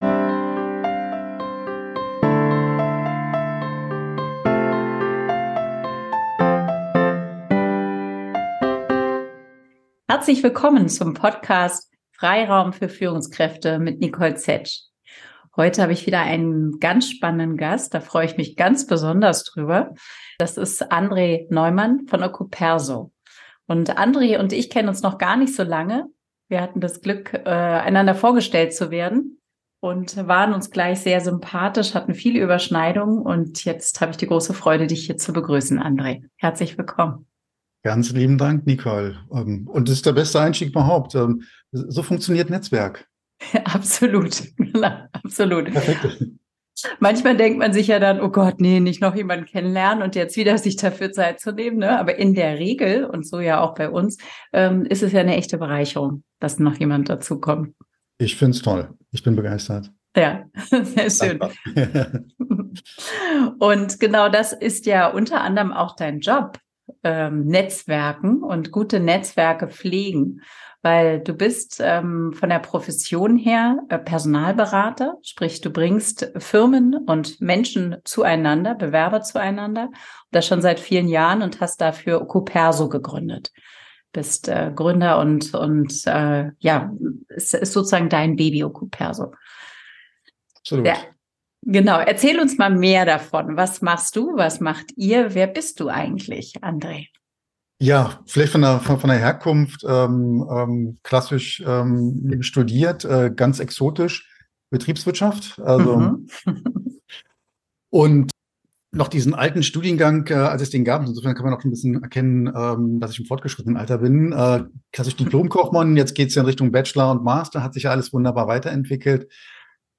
#49 Erfolgreich bewerben und netzwerken – Interview